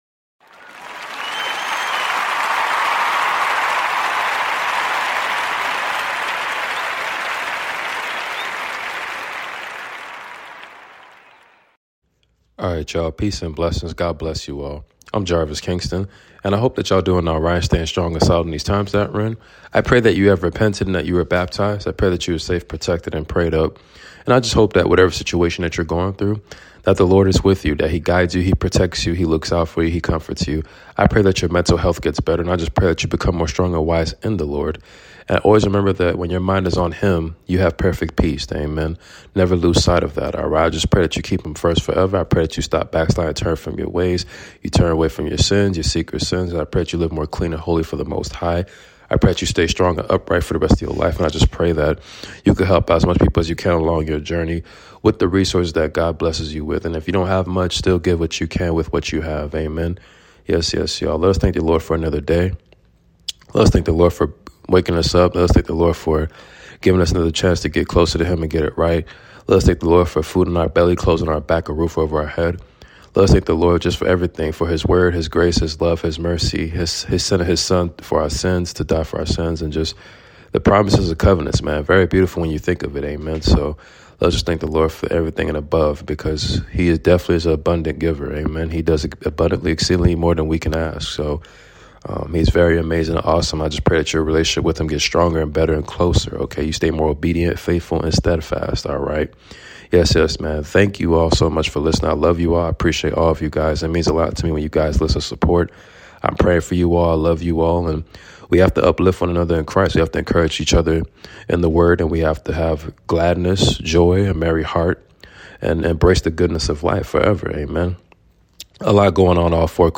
Book of Ephesians reading completion chapters 1-6.